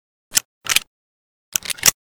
vz61_reload.ogg